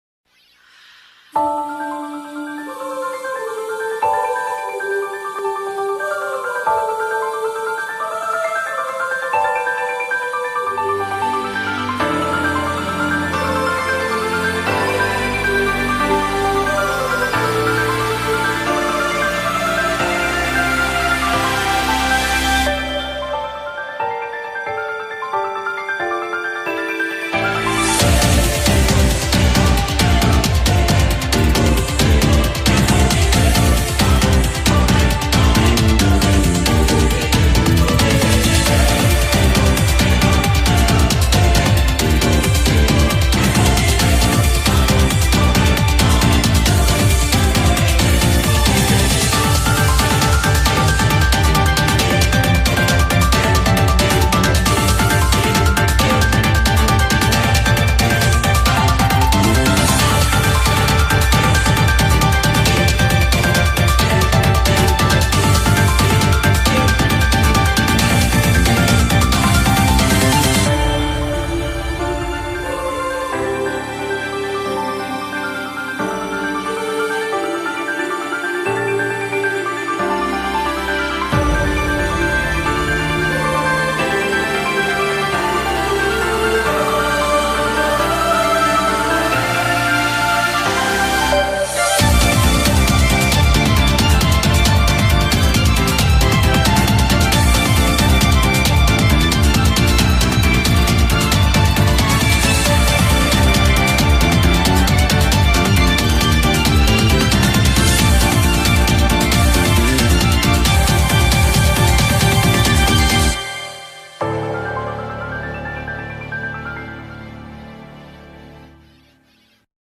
BPM270
Audio QualityLine Out